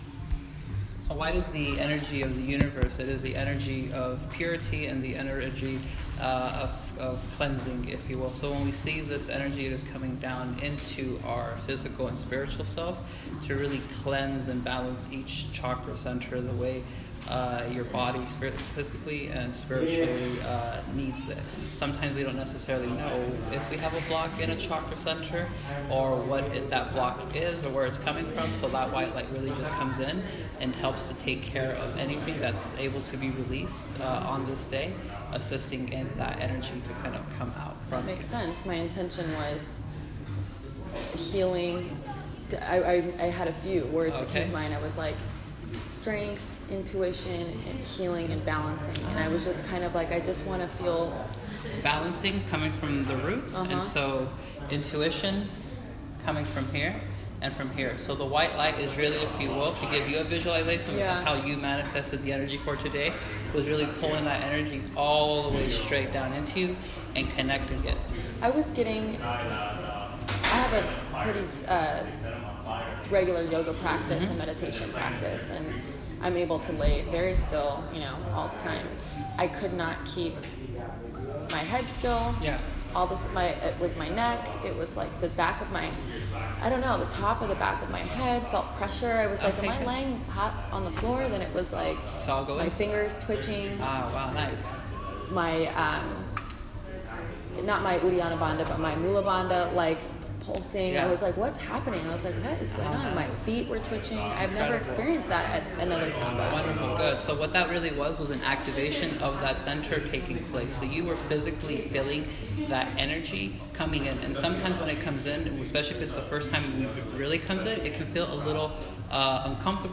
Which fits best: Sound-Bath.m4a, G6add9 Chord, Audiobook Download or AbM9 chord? Sound-Bath.m4a